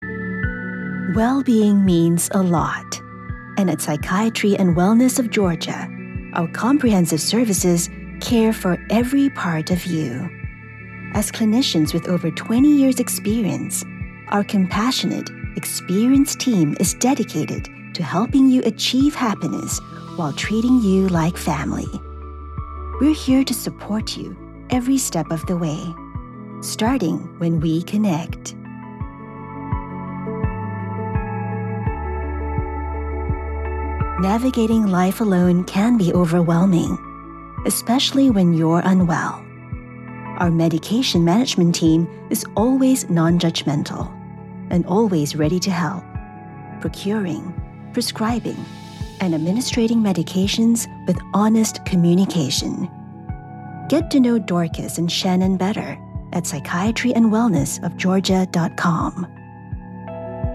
Bahasa (Malaysia)
Yng Adult (18-29) | Adult (30-50)
Phone Greetings & Interactive Phone Prompts